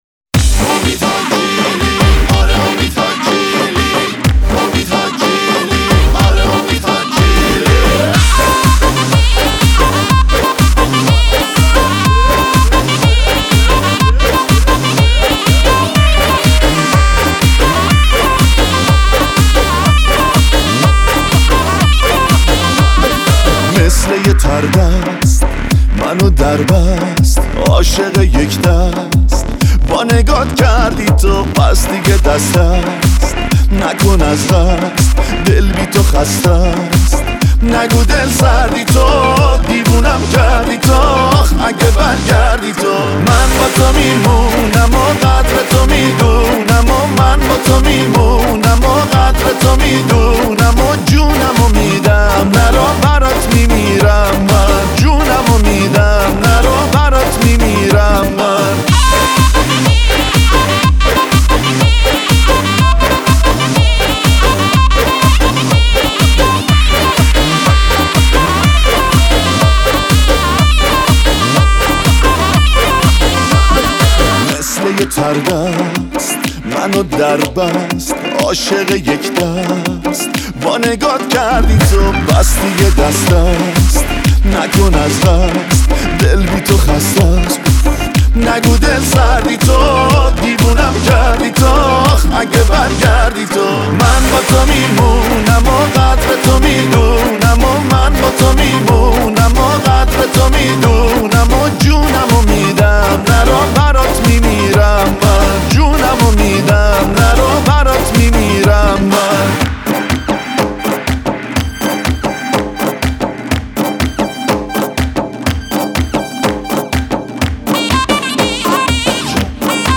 آهنگ شاد